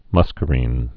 (mŭskə-rēn)